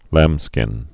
(lămskĭn)